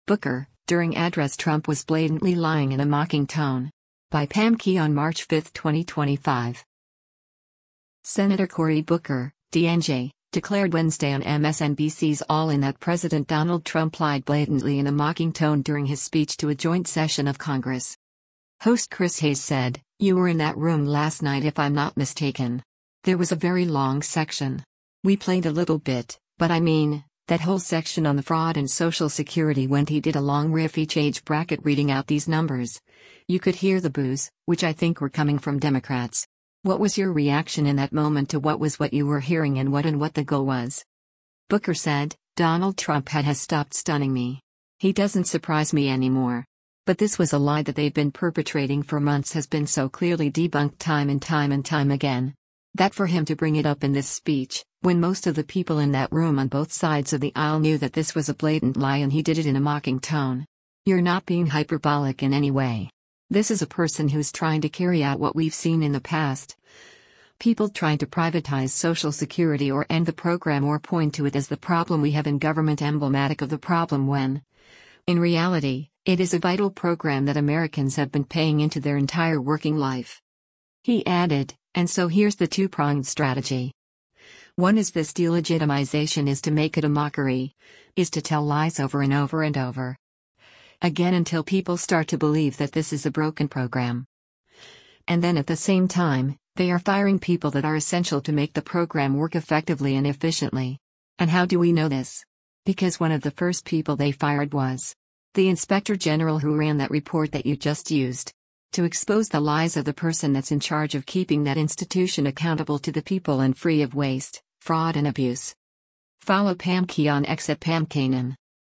Senator Cory Booker (D-NJ) declared Wednesday on MSNBC’s “All In” that President Donald Trump lied blatantly in a mocking tone during his speech to a joint session of Congress.